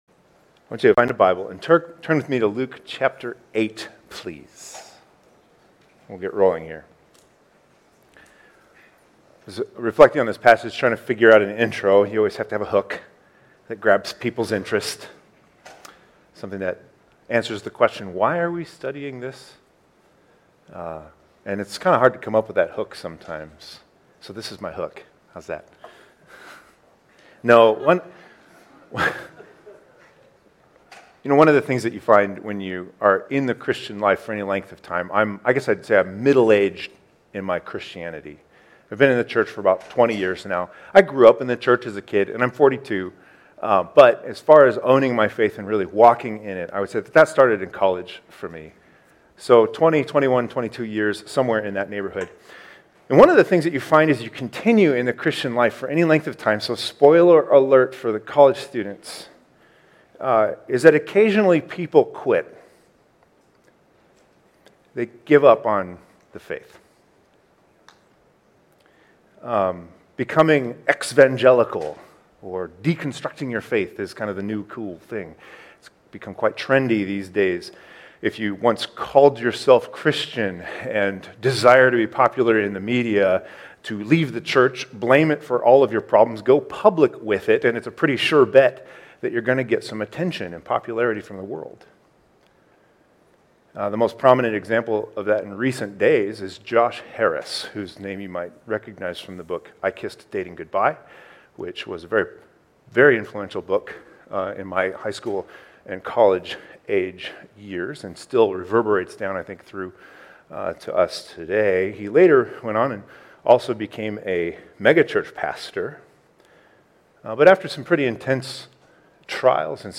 2021 Stay up to date with “ Stonebrook Church Sermons Podcast ”